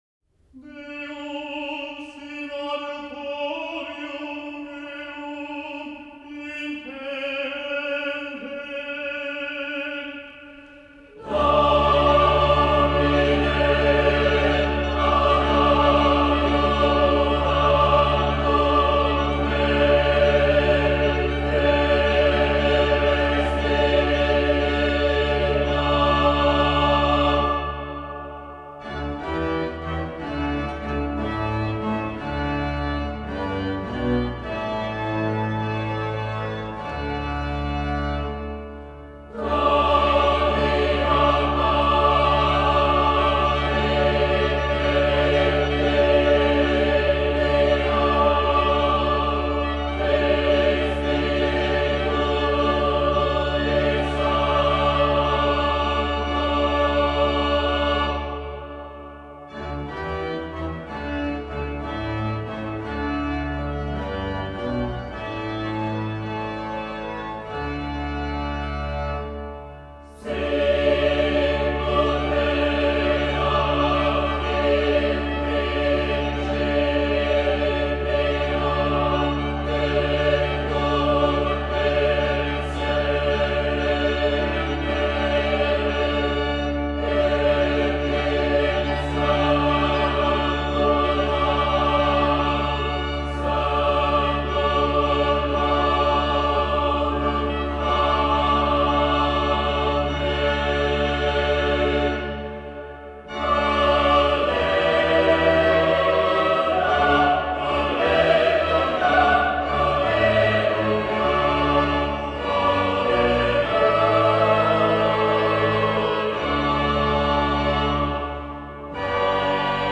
Monteverdi, Claudio, 1567-1643, komponists
Garīgā vokālā mūzika